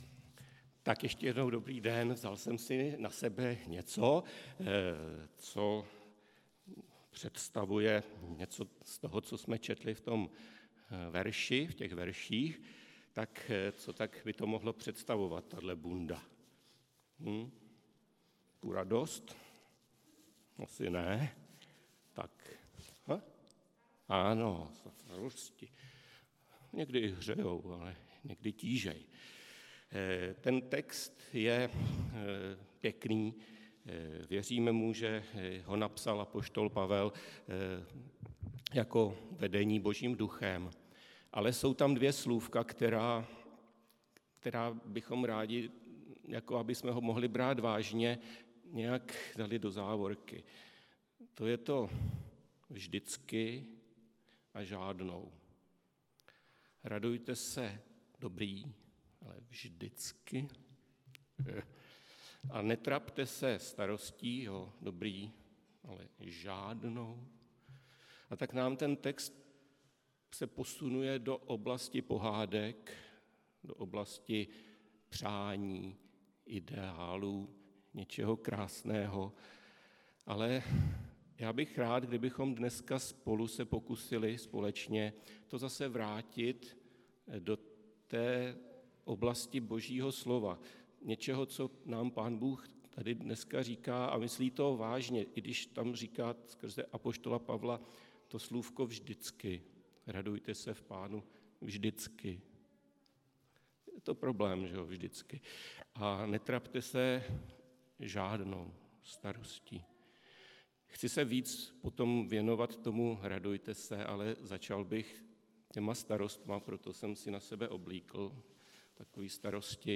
Kázání
Událost: Kázání
Místo: Římská 43, Praha 2